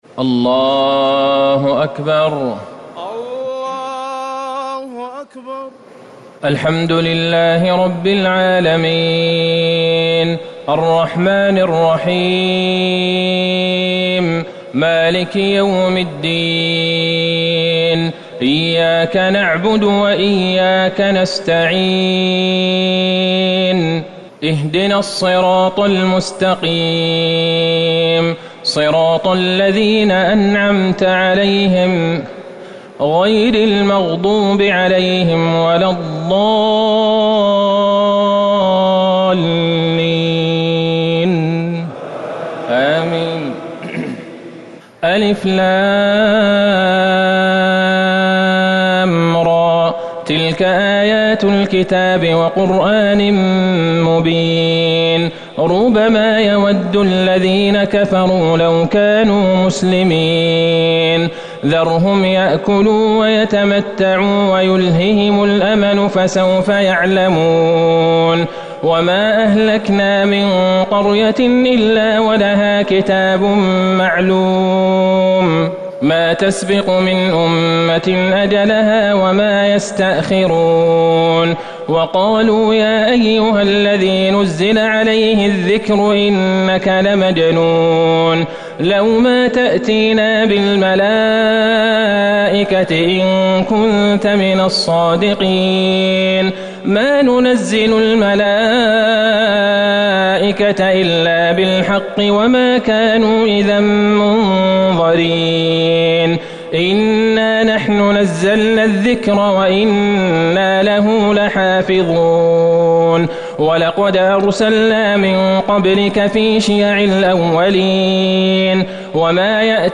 ليلة ١٣ رمضان ١٤٤٠ من سورة الحجر - النحل ٥٢ > تراويح الحرم النبوي عام 1440 🕌 > التراويح - تلاوات الحرمين